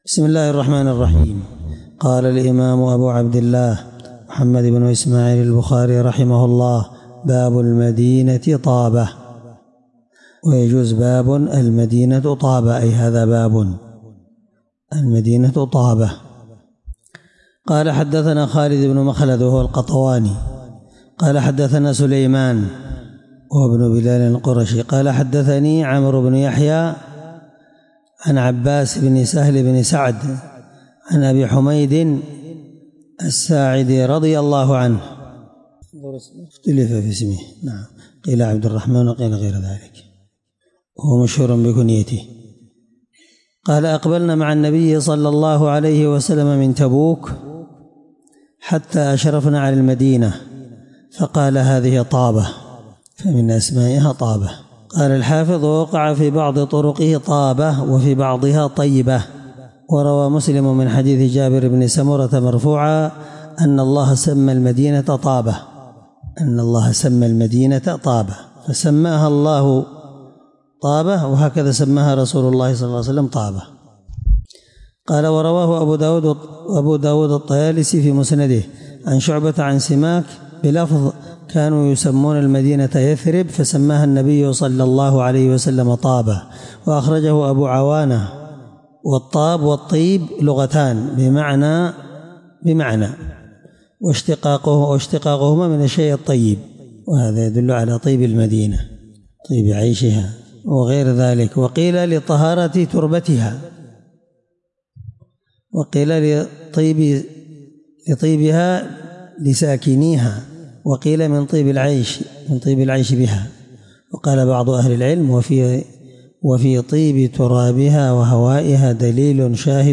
الدرس4من شرح كتاب فضائل المدينة حديث رقم(1872 )من صحيح البخاري